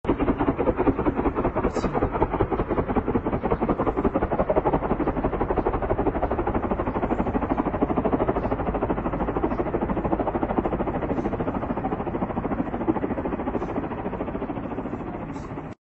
Secondary explosions observed at the site of an Israeli strike earlier in the Northwestern Iranian city of Piranshahr, near the border with Iraq.